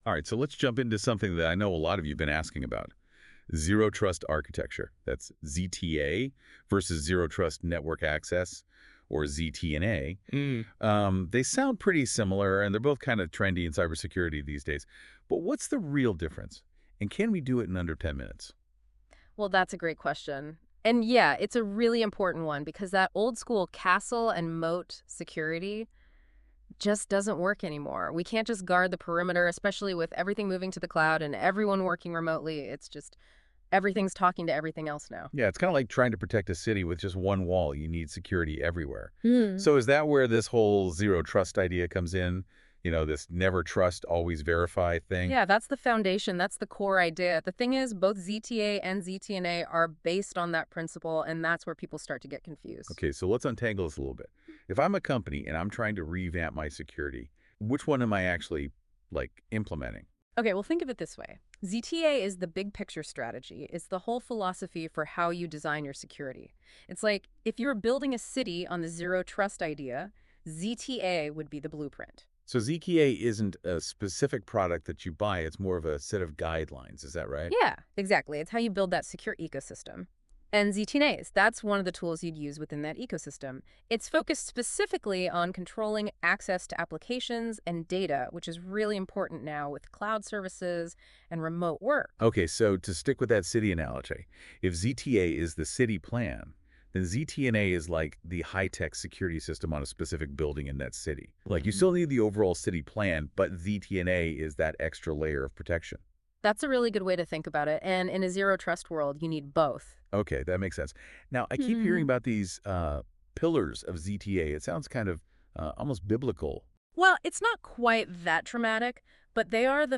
Listen Now Neeve created this Podcast from our original content using AI by NotebookLM